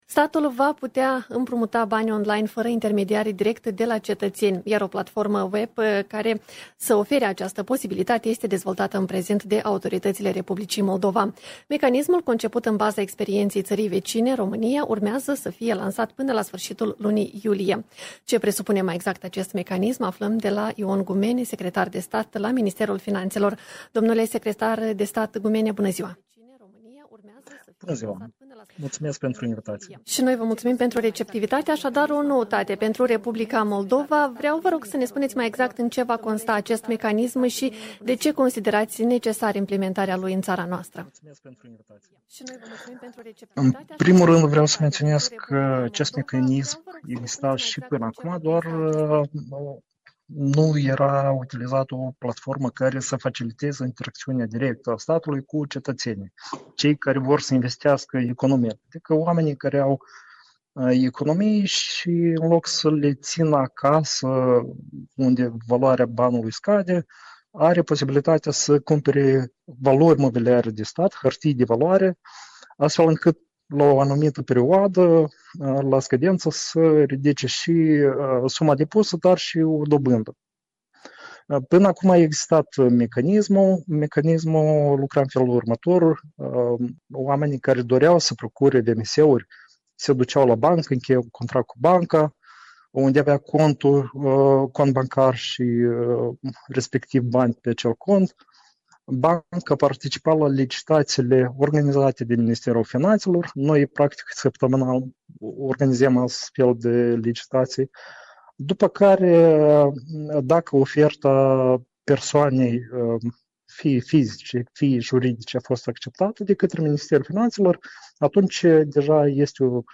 Interviu - Ion Gumene, secretar de stat la Ministerul Finanțelor la Radio Moldova | Ministerul Finanțelor